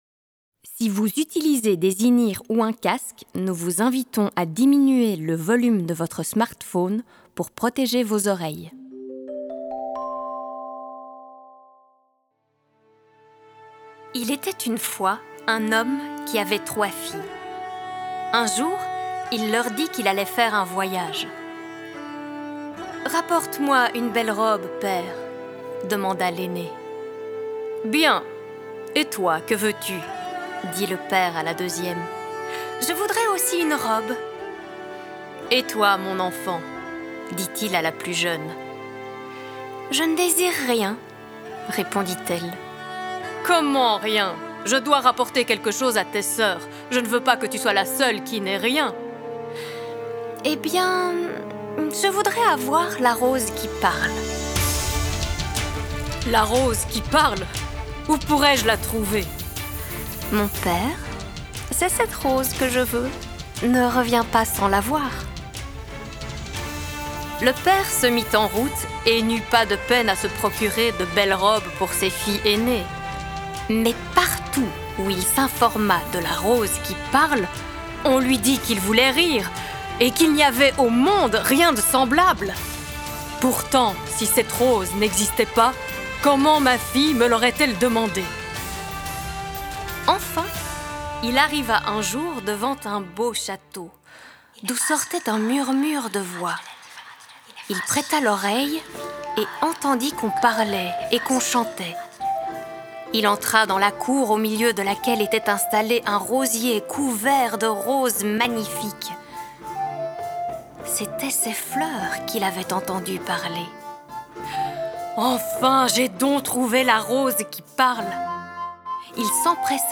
Conte populaire lorrain